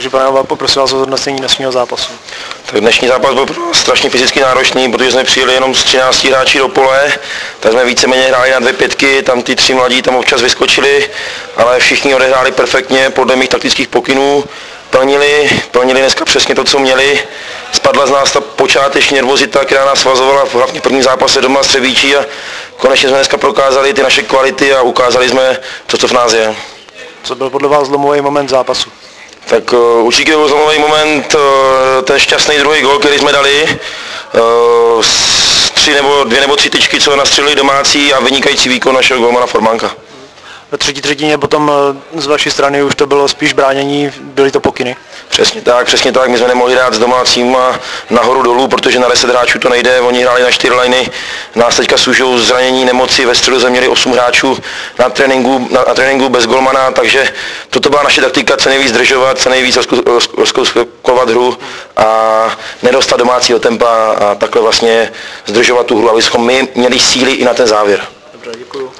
po utkání dorostu TRE- BNJ 1:4